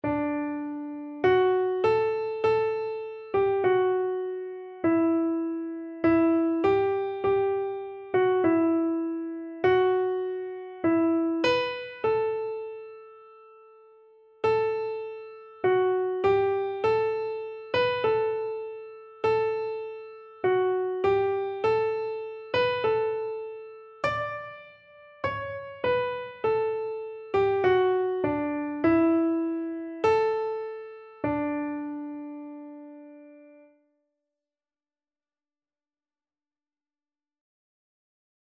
Christian
Free Sheet music for Keyboard (Melody and Chords)
4/4 (View more 4/4 Music)
Keyboard  (View more Easy Keyboard Music)
Classical (View more Classical Keyboard Music)